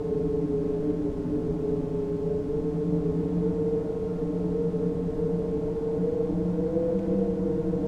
ambiance__bridge.wav